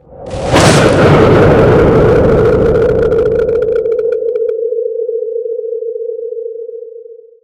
blowout.ogg